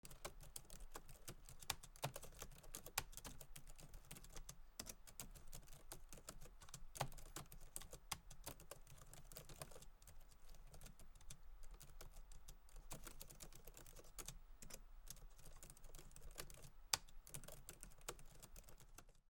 Typing on Laptop; Very Fast
click clicking clicks computer keyboard keys laptop mouse sound effect free sound royalty free Sound Effects